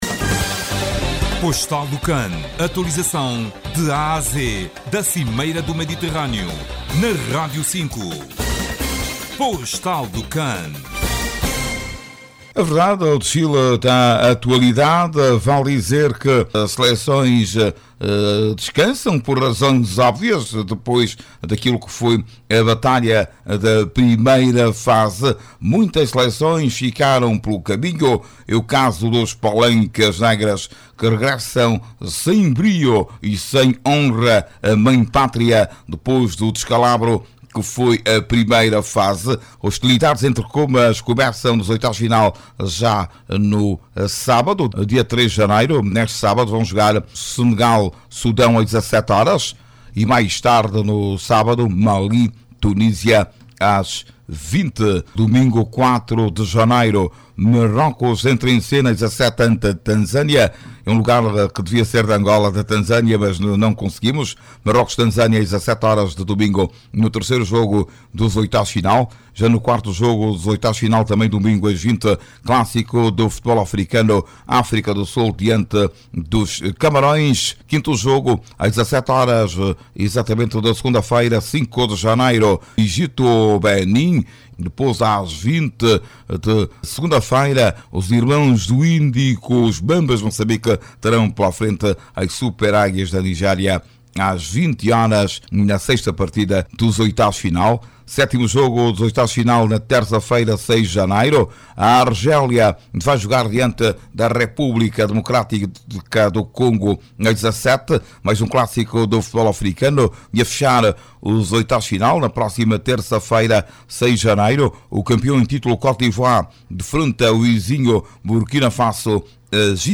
com o plantão da Rádio 5 e o jornalista